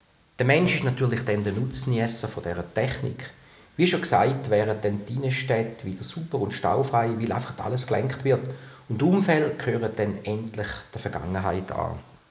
Dieses Interview gibt es auch auf HOCHDEUTSCH!!!